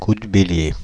Ääntäminen
France (Île-de-France): IPA: [ku dbe.lje]